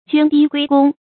涓滴歸公 注音： ㄐㄨㄢ ㄉㄧ ㄍㄨㄟ ㄍㄨㄙ 讀音讀法： 意思解釋： 涓滴： 小水點； 谷喻極小或極少的東西。